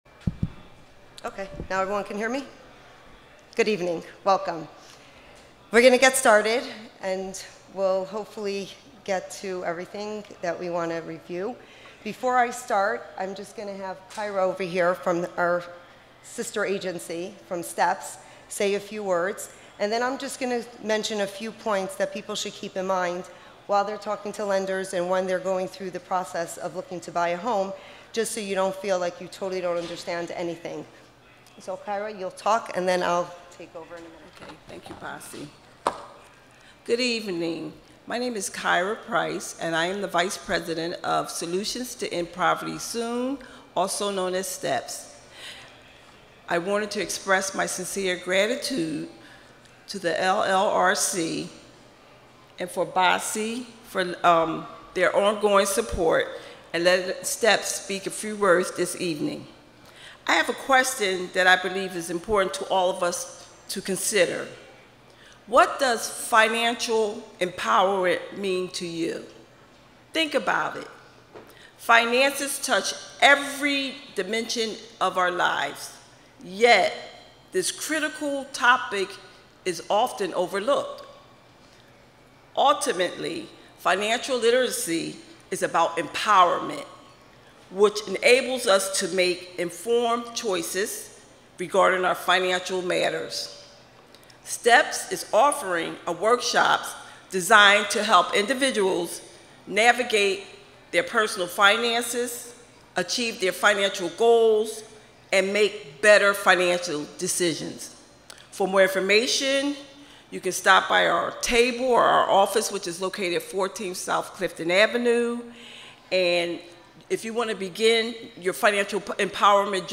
LRRC First Time Home Buyers Event.m4a